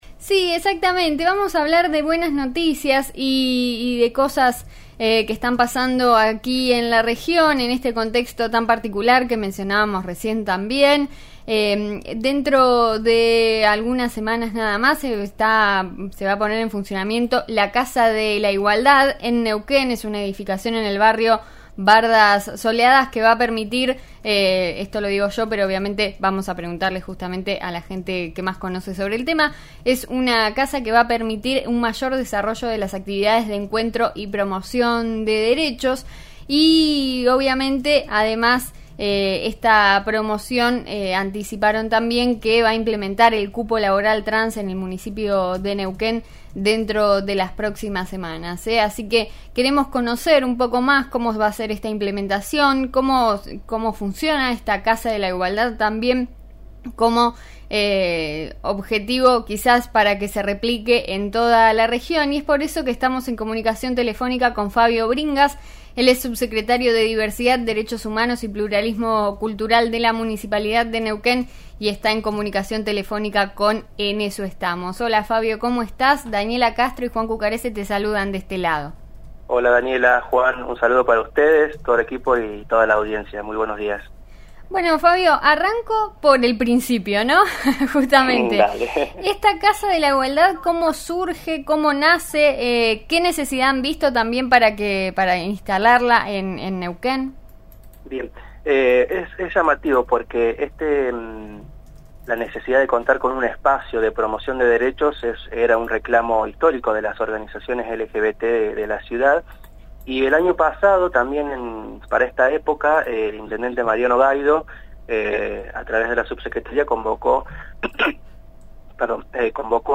El subsecretario de Diversidad, Derechos Humanos y Pluralismo Cultural del municipio de Neuquén pasó por los micrófonos de En Eso Estamos (RN Radio 89.3) y charló sobre varios temas.
Durante la primera nota de este miércoles, el equipo de En Eso Estamos (RN RADIO – FM 89.3 en Neuquén) entrevistó a Fabio Bringas, subsecretario de Diversidad, Derechos Humanos y Pluralismo Cultural de Neuquén; sobre el proyecto de la Casa de la Igualdad y los dos primeros ingresos del cupo trans en el municipio.